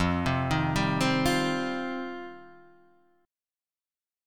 F 7th Suspended 2nd